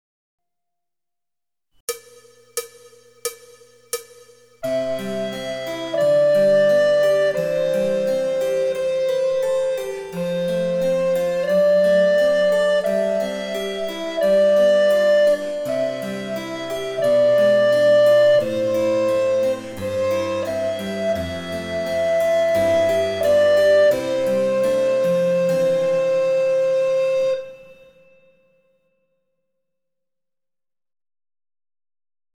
リコーダー演奏